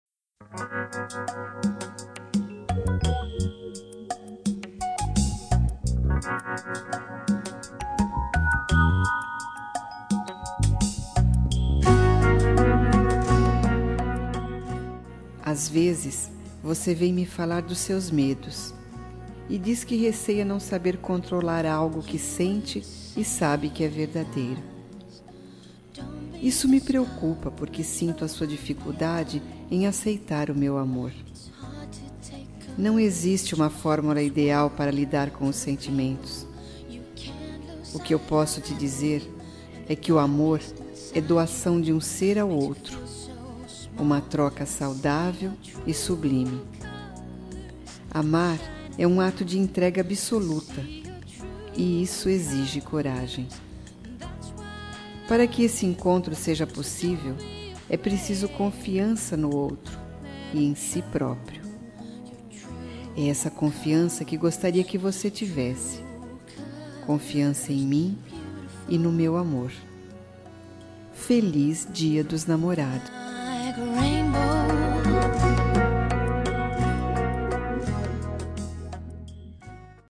Telemensagem Dia Dos Namorados Ficante
Voz Feminina